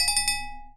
chime_bell_01.wav